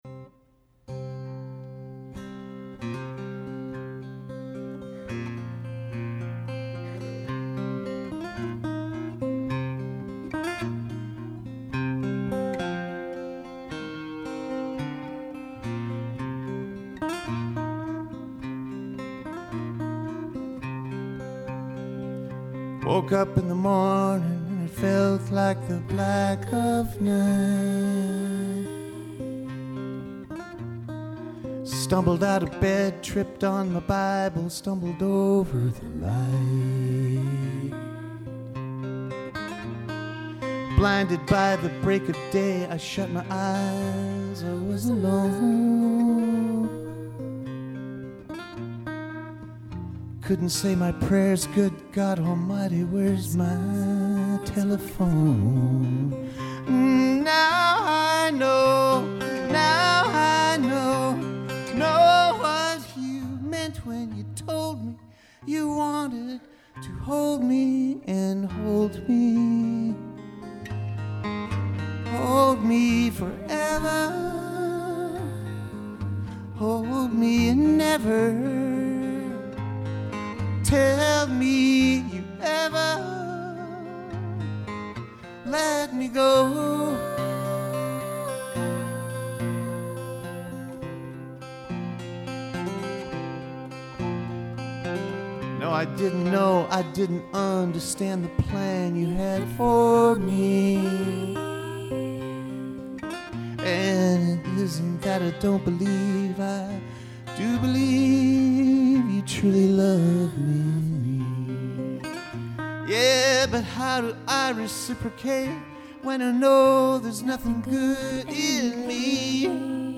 Added Backing Vox: